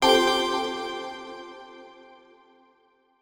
Pickup High.wav